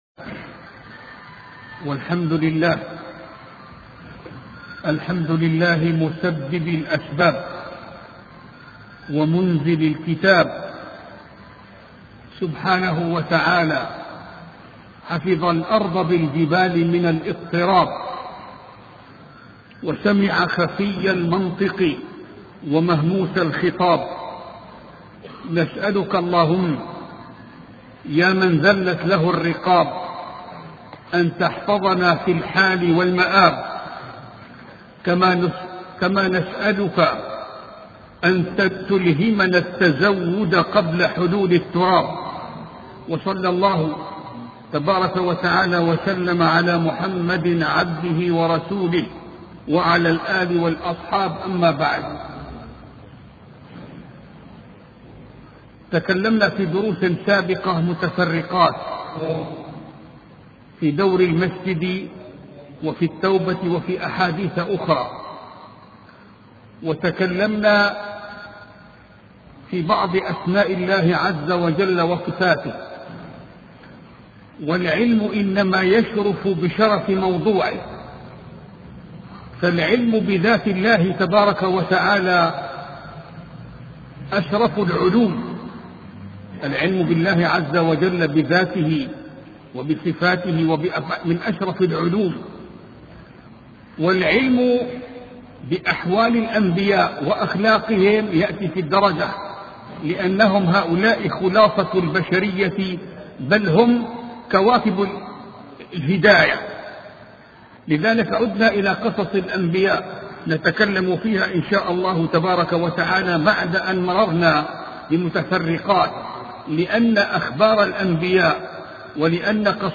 سلسلة محاضرات في قصة داود عليه السلام